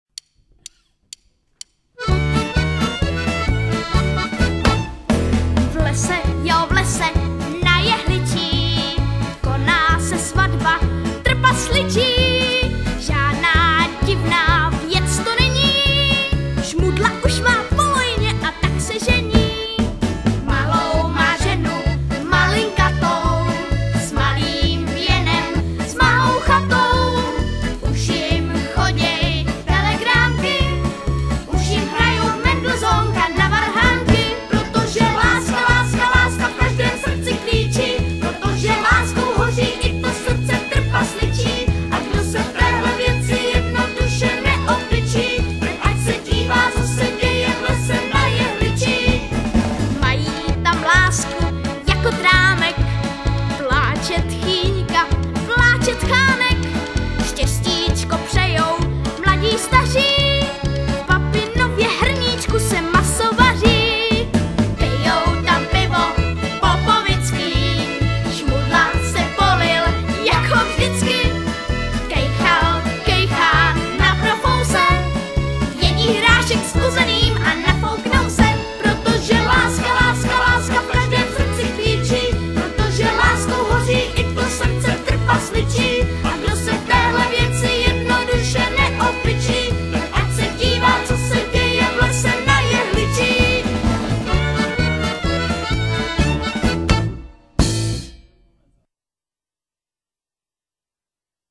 Modřanská svatební verze